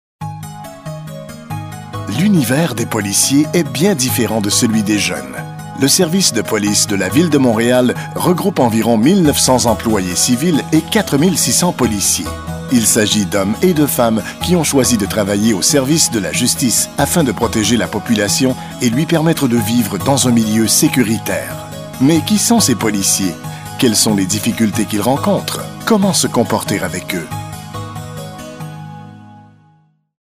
Voix Hors Champ narrateur professionnel producteur radio
Sprechprobe: Industrie (Muttersprache):